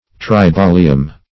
tribolium - definition of tribolium - synonyms, pronunciation, spelling from Free Dictionary